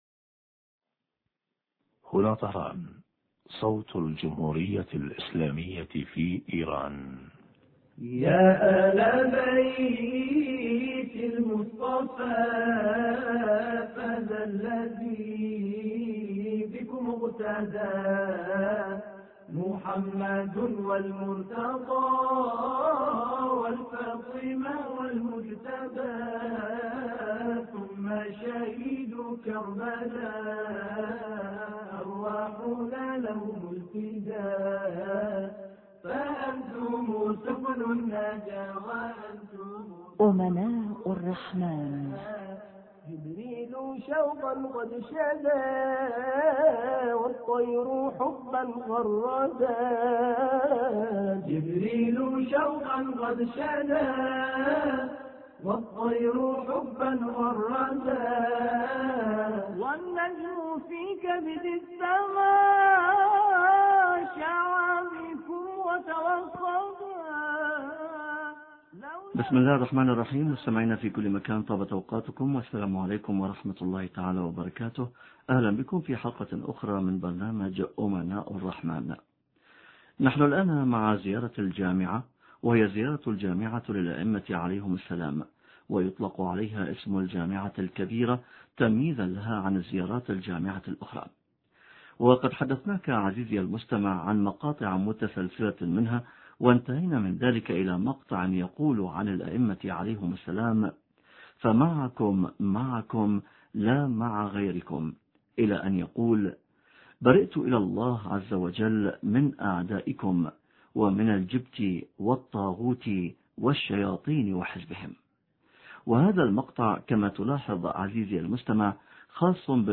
شرح فقرة: برئت الى الله عزوجل من أعدائكم ومن الجبت والطاغوت... حوار